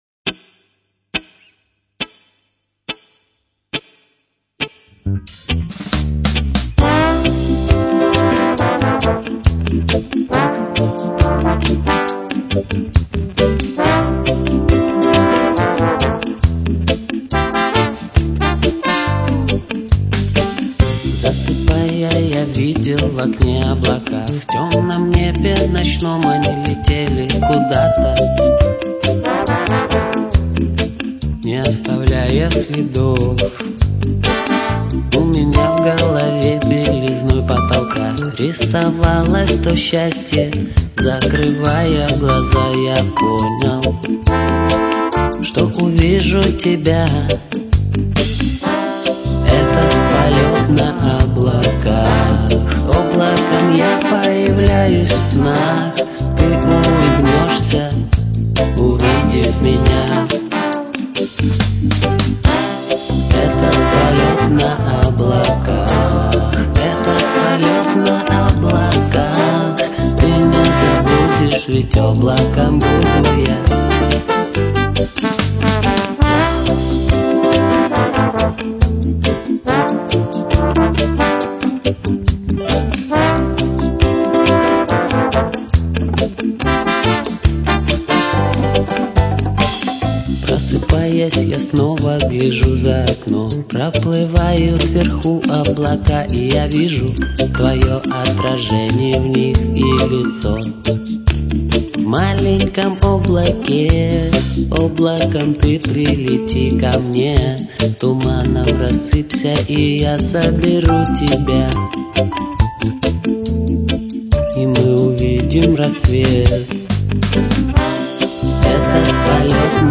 Замечательная регги-группа.